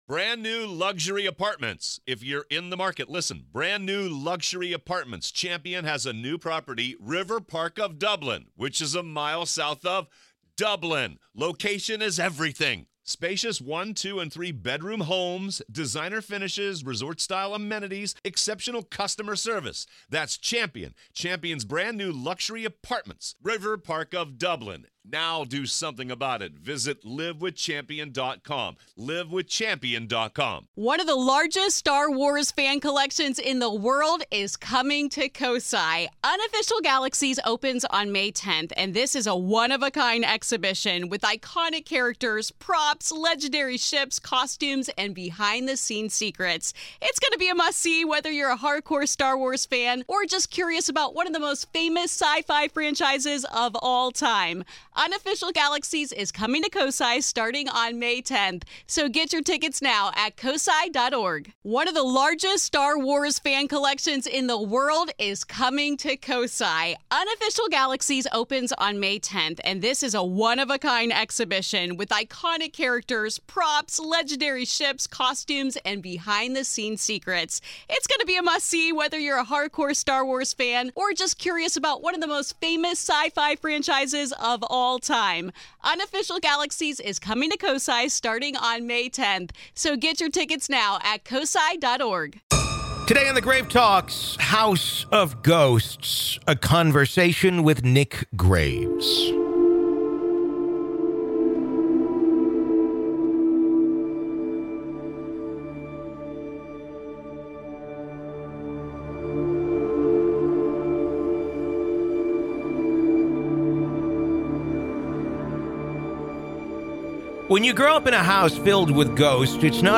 House of Ghosts | A Conversation